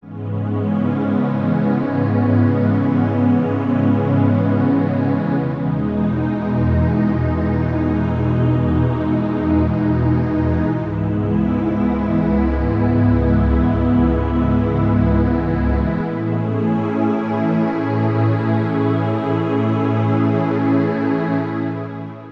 Ну вот, скажем Вложения Nexus Warm Pad1 Dry.mp3 Nexus Warm Pad1 Dry.mp3 699,6 KB · Просмотры: 309 Atmosphere Warm and Blue Dry.mp3 Atmosphere Warm and Blue Dry.mp3 699,6 KB · Просмотры: 306